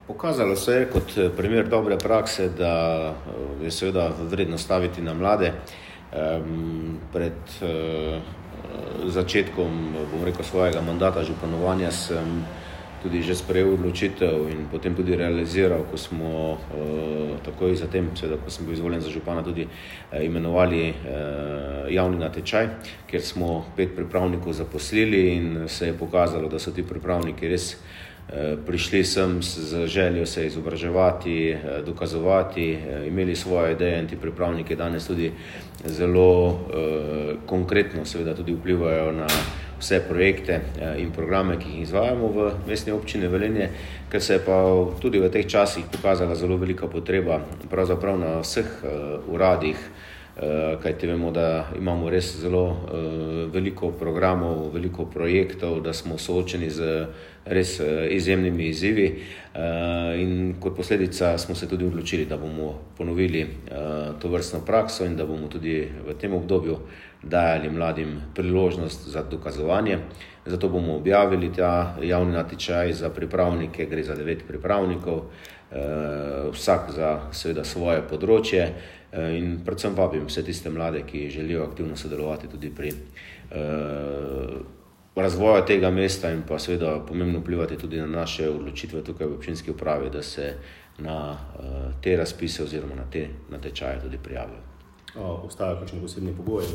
izjavo župana Mestne občine Velenje Petra Dermola.